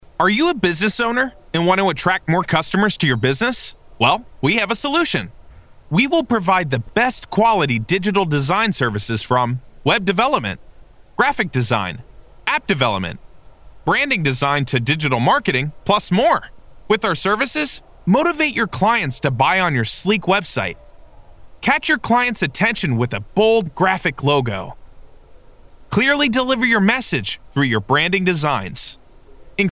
英语配音美式英语配音
• 男英8 美式英语 欢快激情的讲解叙述 激情激昂|娓娓道来|科技感|积极向上|时尚活力|神秘性感|亲切甜美|感人煽情|素人|脱口秀